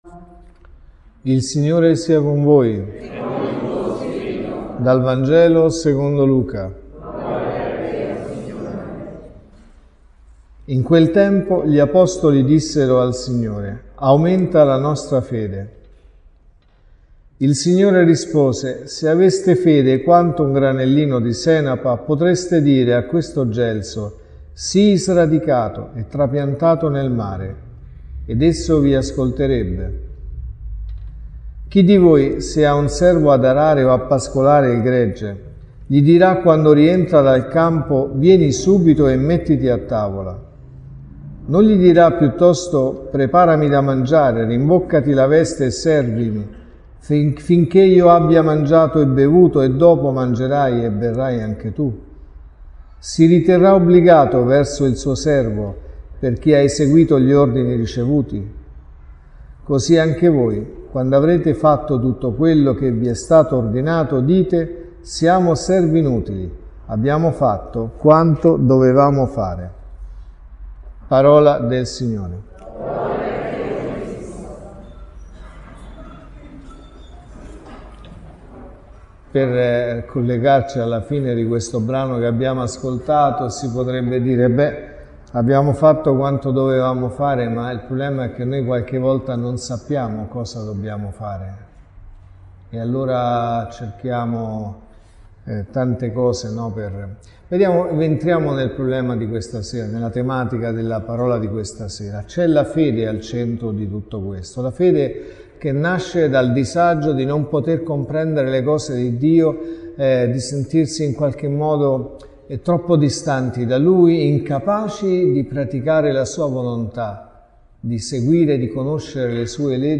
(Messa del mattino e della sera) | Omelie LETTURE: Vangelo, Prima lettura e Seconda lettura Dal Vangelo secondo Luca (Lc 17,5-10) In quel tempo, gli apostoli dissero al Signore: «Accresci in noi la fede!».